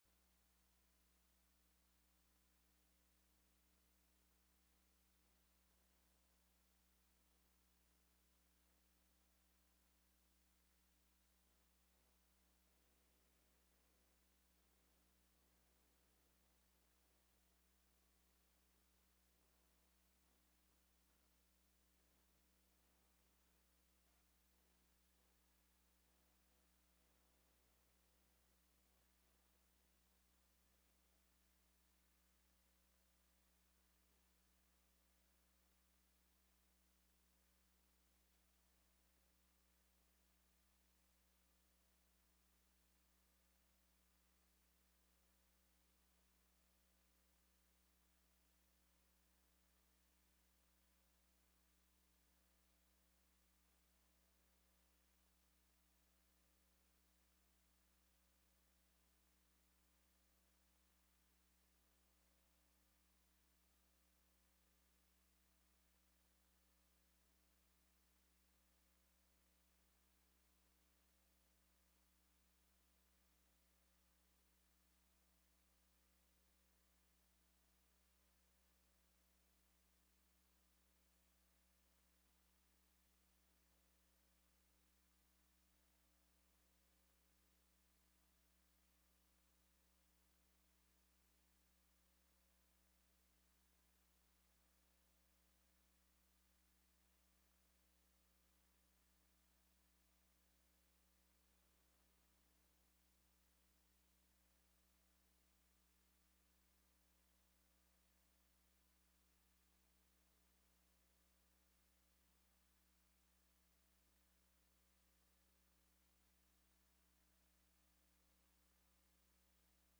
i play old scratchy records